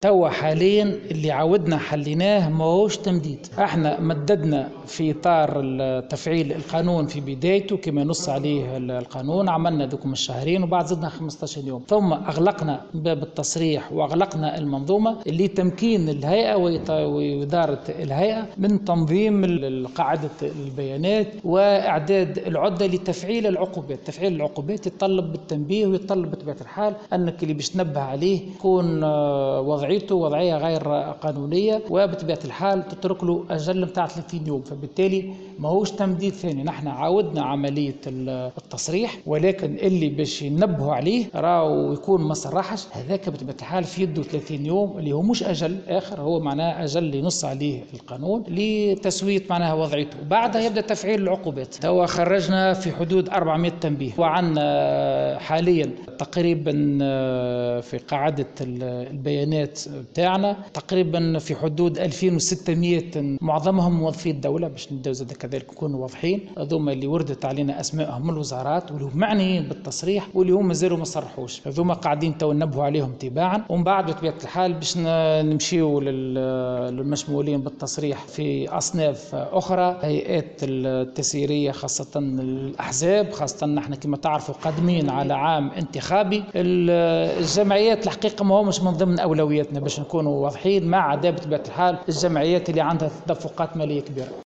و بيّن "الطبيب" في تصريح للجوهرة "أف أم"، أنّ الهيئة أصدرت 400 تنبيه للأشخاص المعنيين بالتصريح بالمكاسب، مشيرا إلى أنّ قاعدة البيانات بالهيئة تضم قائمة لـ 2600 شخص معظمهم من موظفي الدولة المعنيين بالتصريح بمكاسبهم وردت أسمائهم من الوزارات المعنية و لم يقوموا بعملية التصريح.